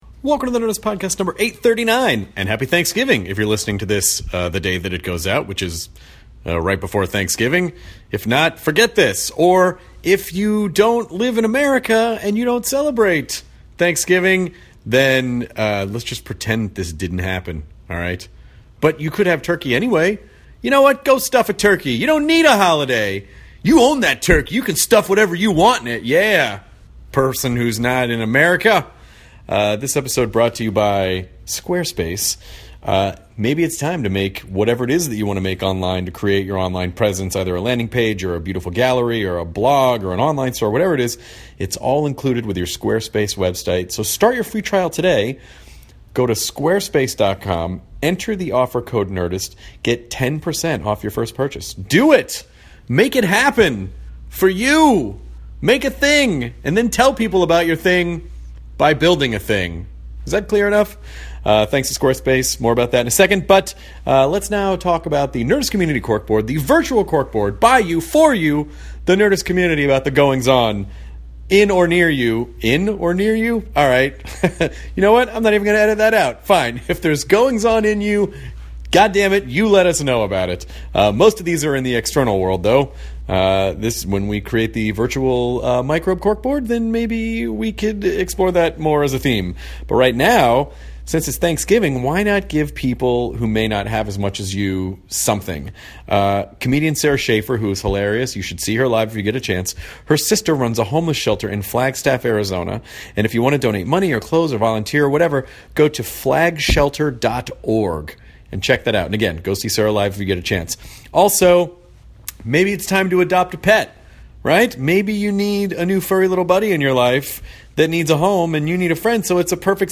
Jeremy Irons (Die Hard with a Vengeance, The Lion King) chats with Chris about why he wanted to be an actor, how he chooses the roles he does and the importance of learning about different cultures. Jeremy also reveals how he always dreamed of being a clown and they talk about his recent film The Man Who Knew Infinity!